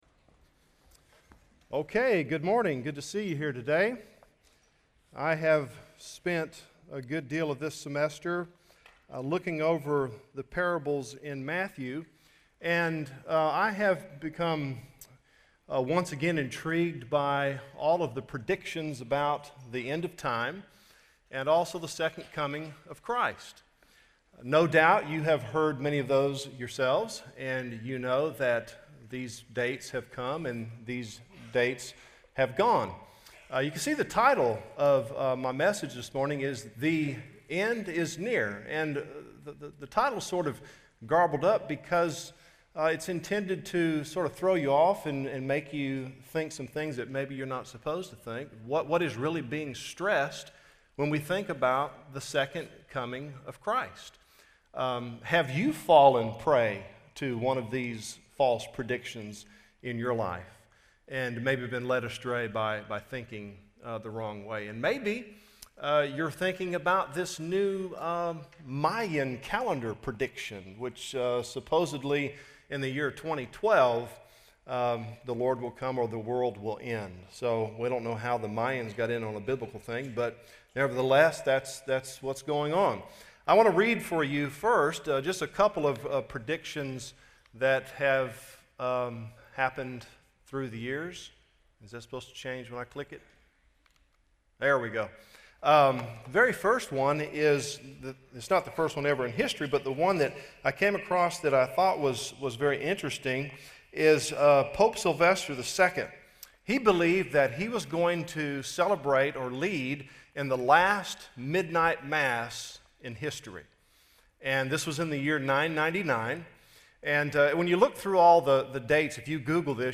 Chapel
Union University Address: The End Is Near! - Matthew 24:36-25:30